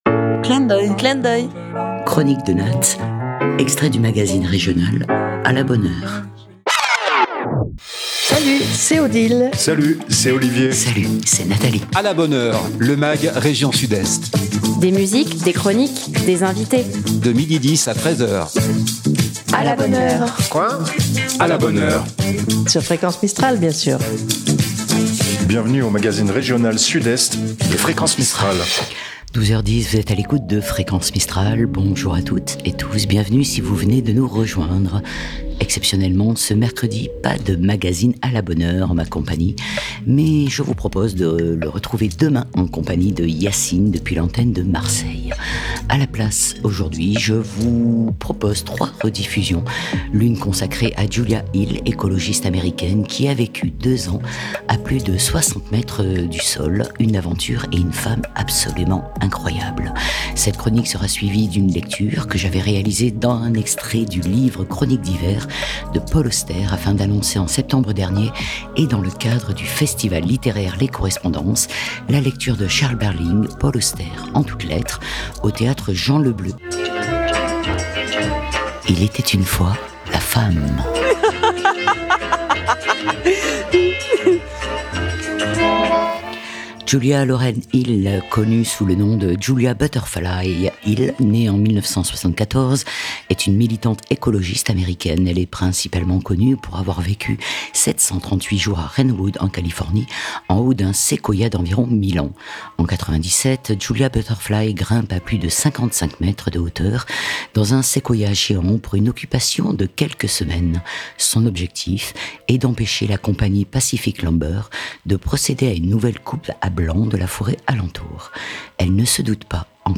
- Lecture - extrait de "Chroniques d'hiver " de Paul Auster, écrivain "A La Bonne Heure", le mag quotidien régional de Fréquence Mistral. 12h10- 13h00 du lundi au vendredi.